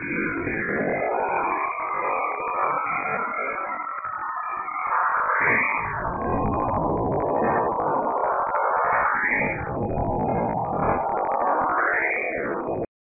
A good site of “sounds of space” collected by U Iowa instruments on various spacecraft.
Here you can find many sounds “recorded” (remember that this are radio frequencies not audio frequencies) by Cassini, Voyagers and Galileo spacecrafts during the Jupiter and Saturn missions.